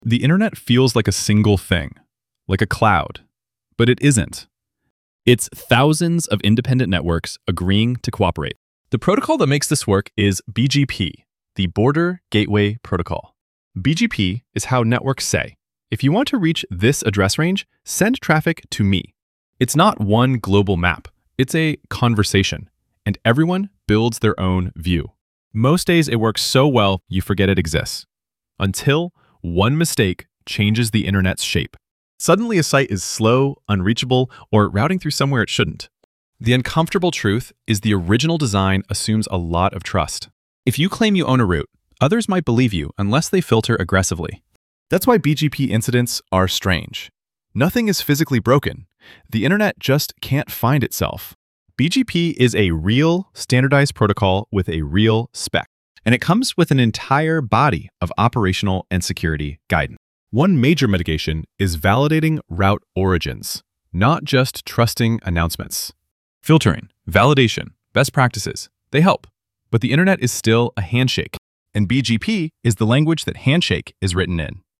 Voiceover-only + script/storyboard.